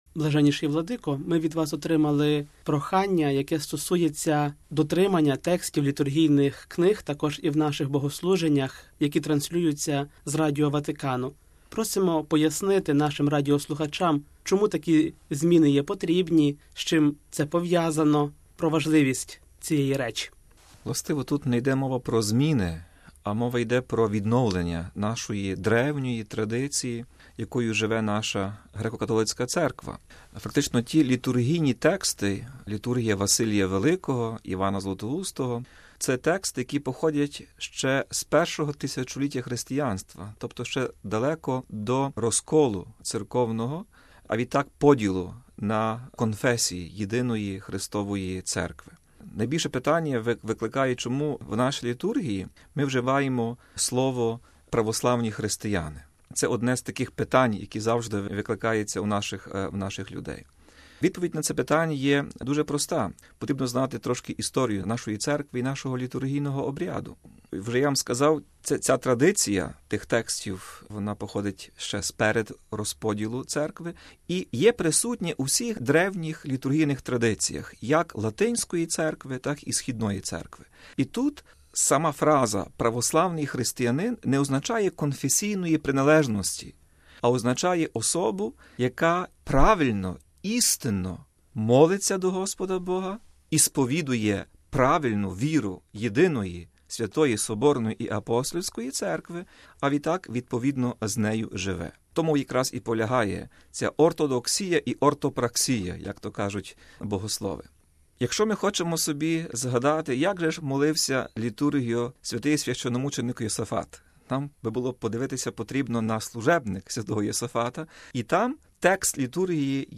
А ось, як на прохання пояснити необхідність дотримання літургійних текстів, відповів Блаженніший Святослав